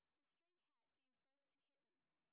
sp16_street_snr30.wav